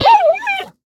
mob / panda / death1.ogg